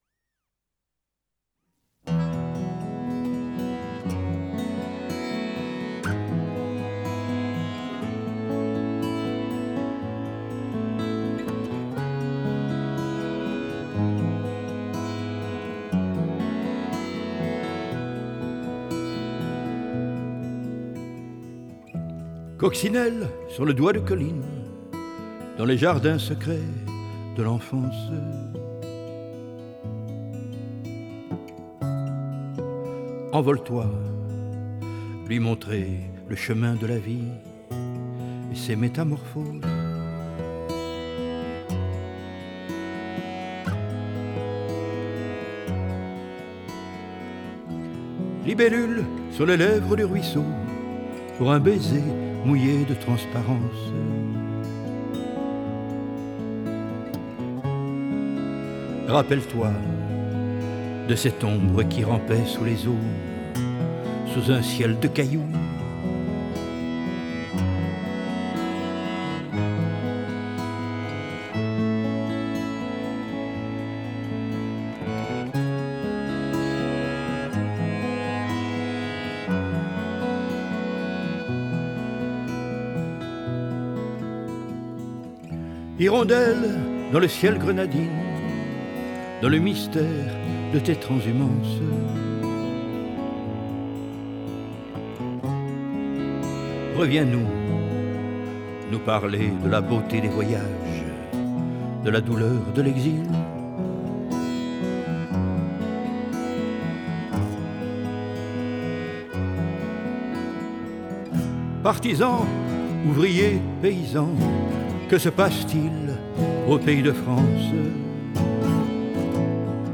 voix, gt
accordéon
basse